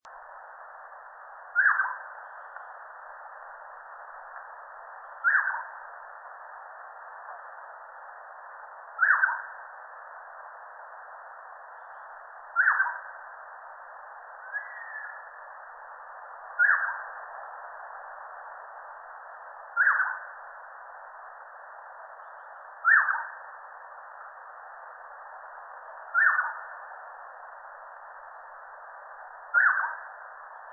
大彎嘴 Pomatorhinus erythrocnemis
嘉義縣 阿里山 馬寮溪
錄音環境 闊葉林
鳥叫
收音: 廠牌 Sennheiser 型號 ME 67